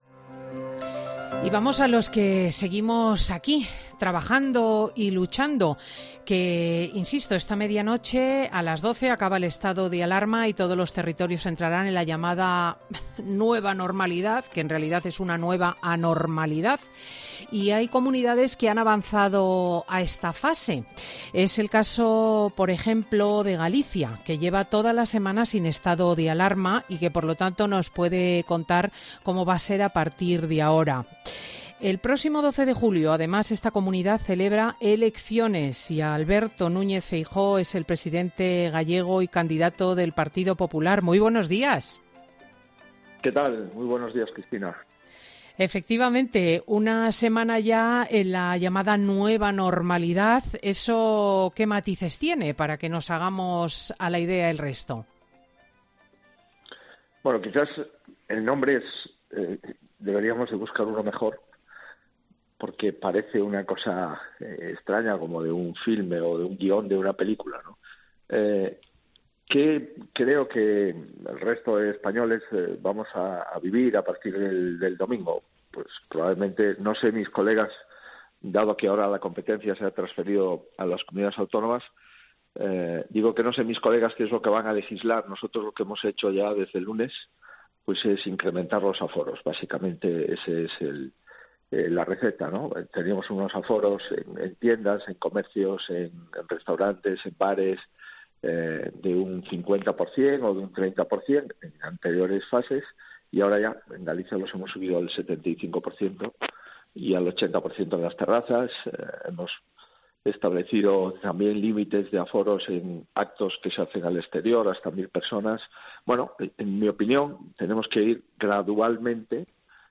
El presidente de Galicia, Alberto Núñez Feijóo , ha criticado este sábado en COPE el apoyo de Podemos a la propuestas de los partidos nacionalistas vascos de investigar en el Congreso del ex presidente del Gobierno, Felipe González , por su presunta implicación en la trama de los GAL.